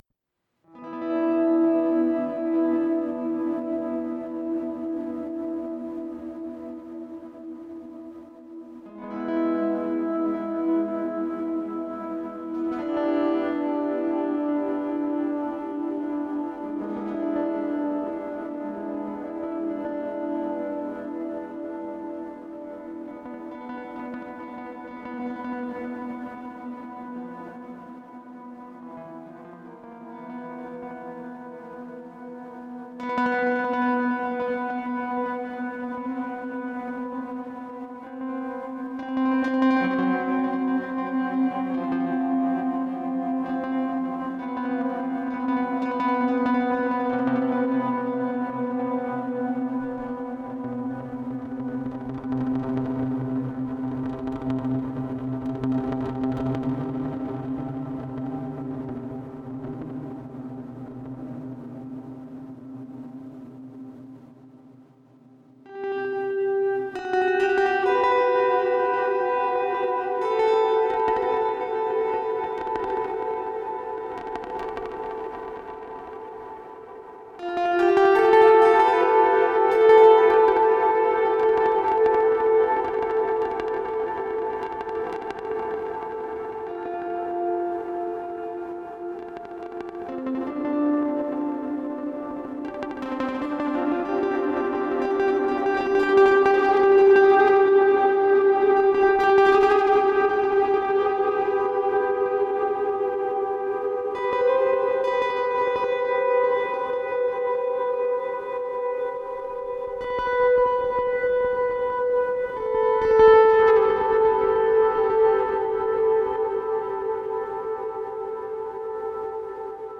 Testing a piece of equipment that just came back from the repair shop this evening, and came across this gem of an acoustic guitar sound.
Recently, I've been only capturing things in real-time performance, with no editing and VERY little mixing after the fact.
Acoustic guitar only. :) Fun times, weird music.
Space out with me, if you dare.